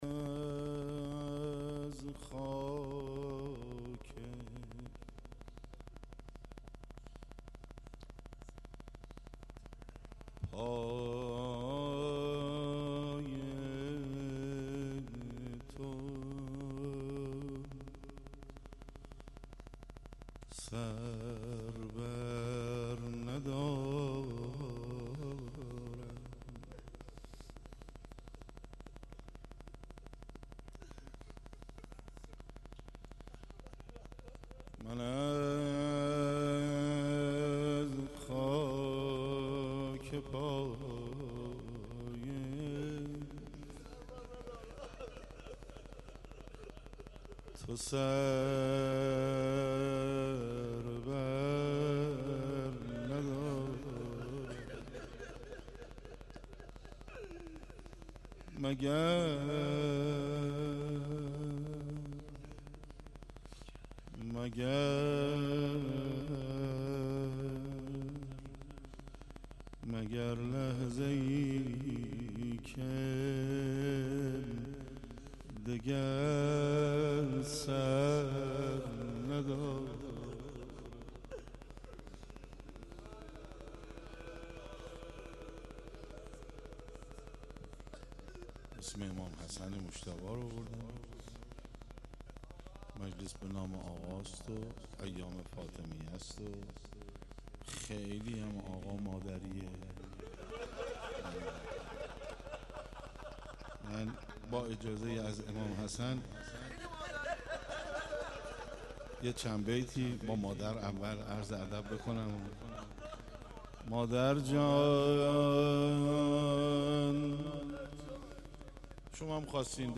مناسبت : شهادت حضرت فاطمه زهرا سلام‌الله‌علیها1
قالب : روضه